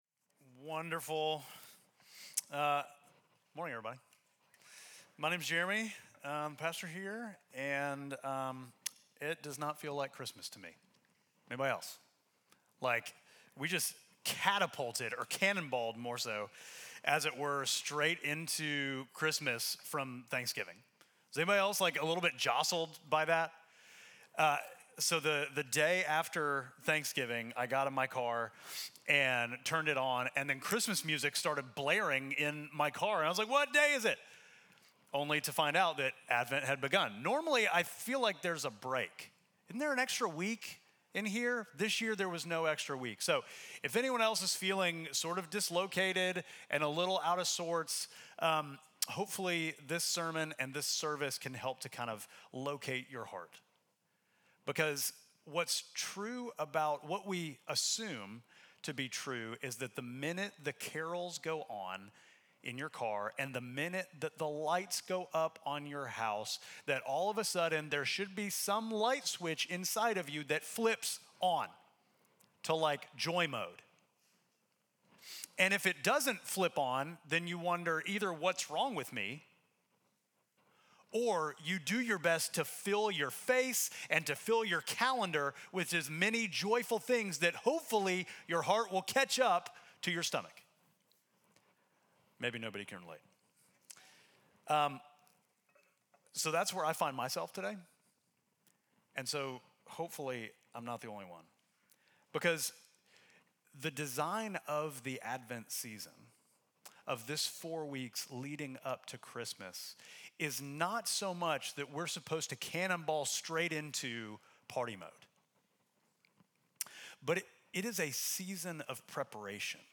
Midtown Fellowship Crieve Hall Sermons Hope in the Wasteland Dec 01 2024 | 00:41:04 1x 00:00 / 00:41:04 Subscribe Share Apple Podcasts Spotify Overcast RSS Feed Share Link Embed